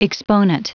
Prononciation du mot exponent en anglais (fichier audio)
Prononciation du mot : exponent